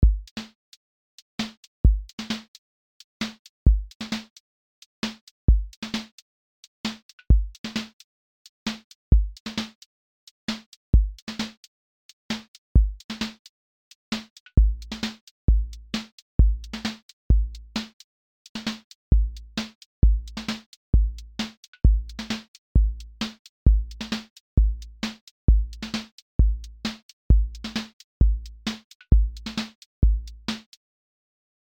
QA Listening Test contemporary-rap Template: contemporary_rap_808
• macro_contemporary_rap_core
• voice_kick_808
• voice_snare_boom_bap
• voice_hat_trap
• voice_sub_pulse
• tone_warm_body
• fx_drum_bus_glue